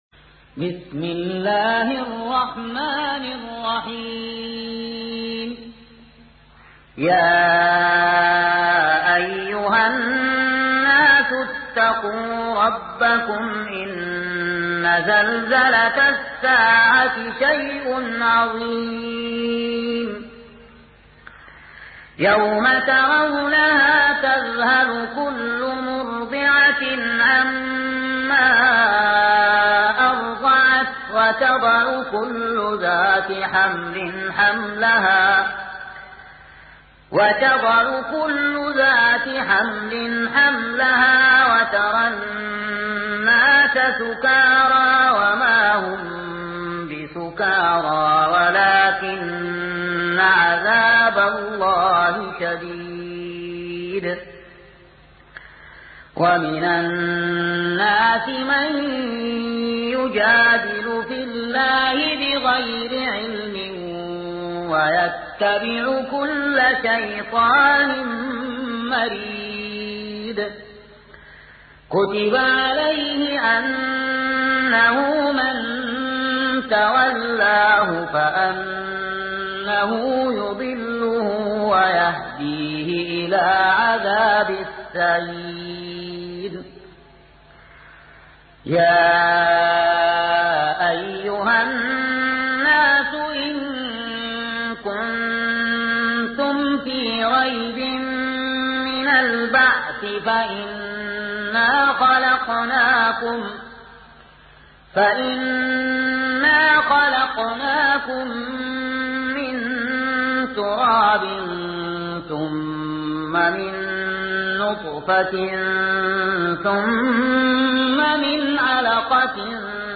سورة الحج | القارئ